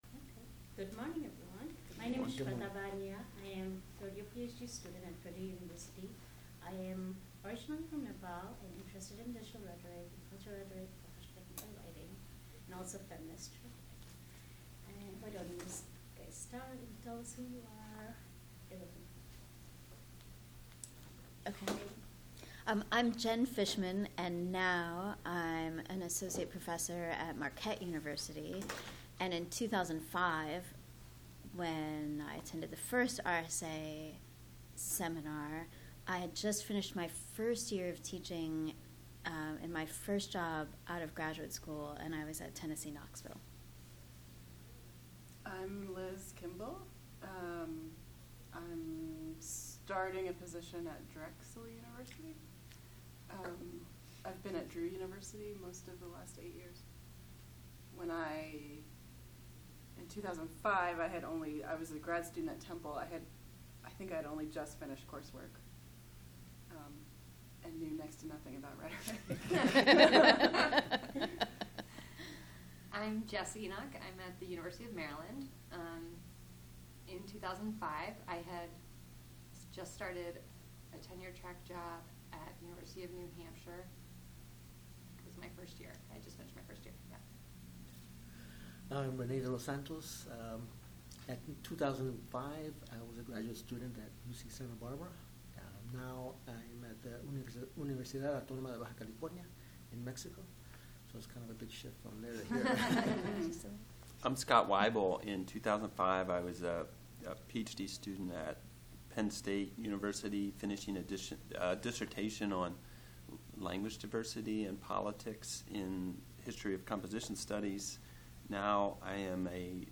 Roundtable on Inaugural RSA Institute Dublin Core Title Roundtable on Inaugural RSA Institute Description Roundtable interview featuring five scholars who participated in the inaugural RSA institute in 2005
Oral History
Location 2018 RSA Conference in Minneapolis, Minnesota